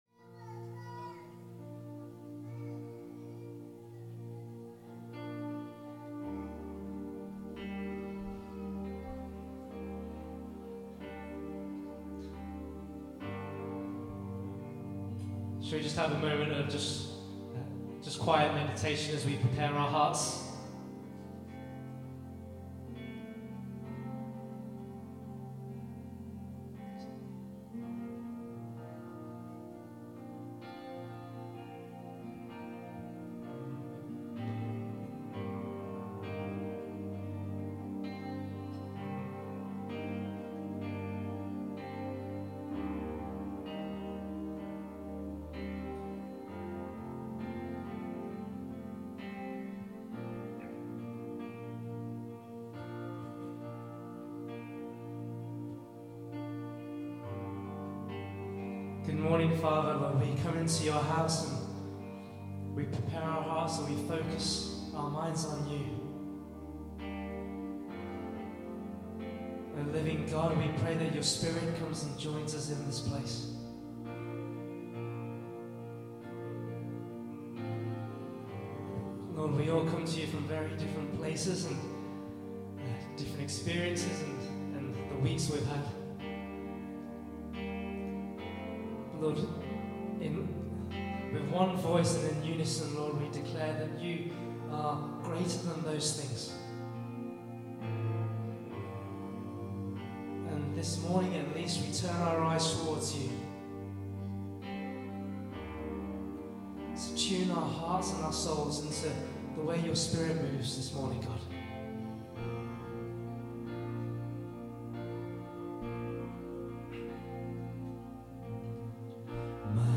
Worship March 15, 2015 – Birmingham Chinese Evangelical Church
Vocals
Electric Guitar
Drums